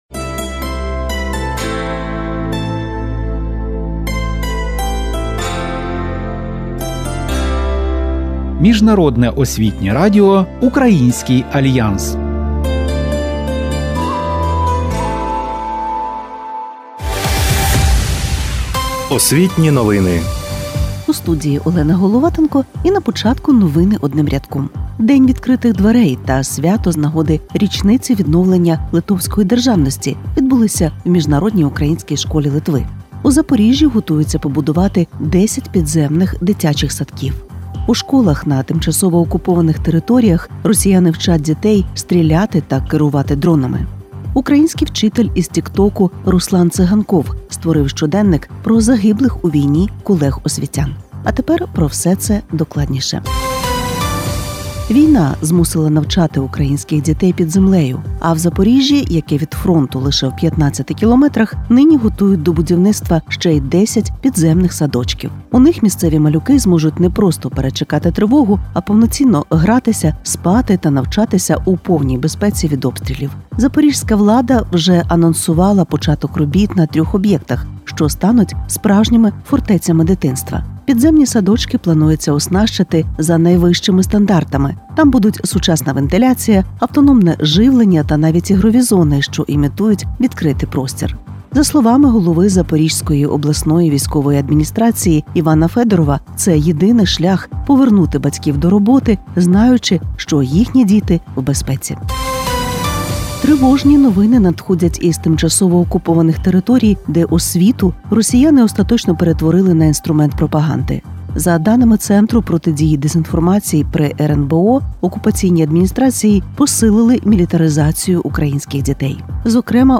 У програмі: підземні садочки Запоріжжя та мілітаризація освіти на окупованих територіях, масштабний День відкритих дверей у МУШ Литви, історичний зв’язок Йосипа Сліпого та литовських «лісових братів», урок литовської про числівники, правда про біткоїн та унікальний спів українського лірника.